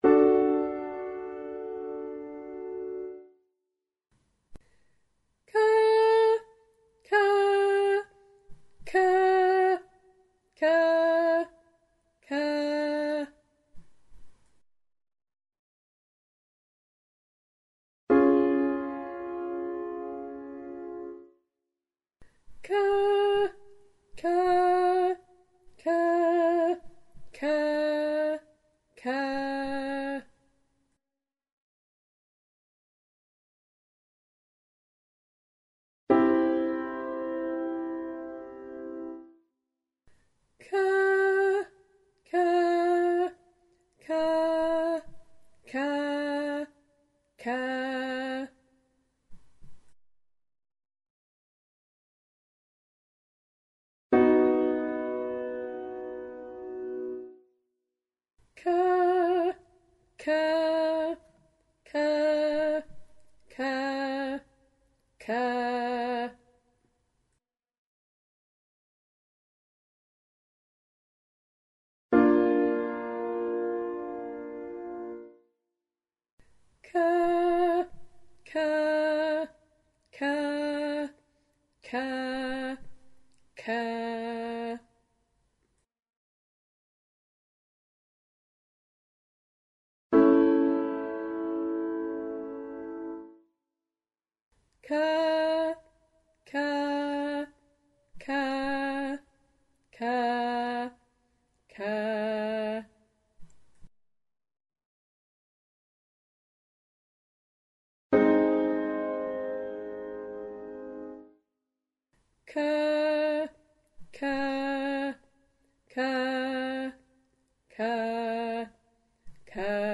76D Separated /kă/ as in “trap”